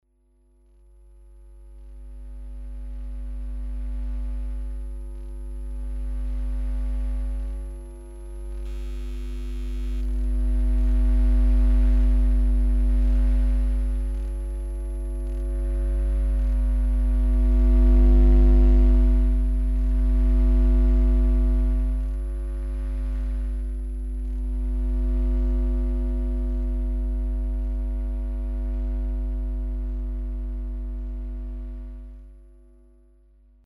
In order to acoustically illustrate the sounds of public space that cannot be heard by human ears but can be registered with help of special equipment, for the beginning as a short introduction to the topic, following sounds were recorded in Ljubljana:
Old electric plant transformer 230V in Ljubljana
old_electric_plant_trasformator-230v.mp3